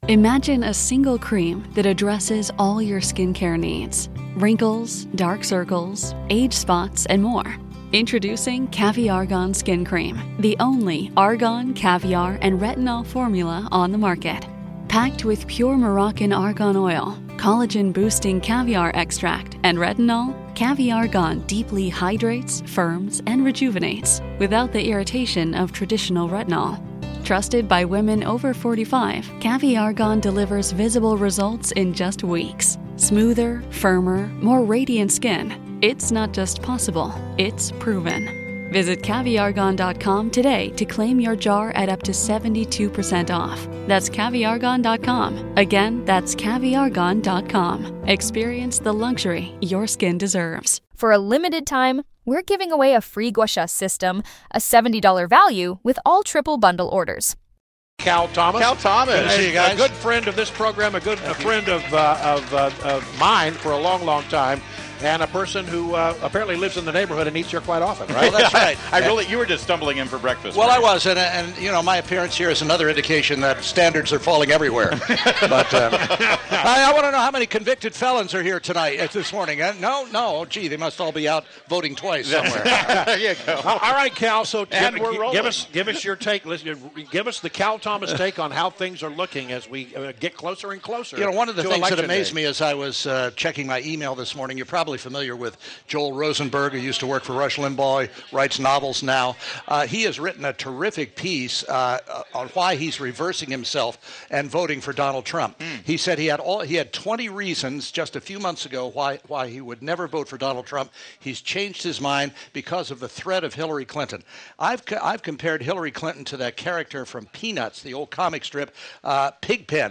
INTERVIEW – CAL THOMAS – syndicated columnist – shared his thoughts on the election.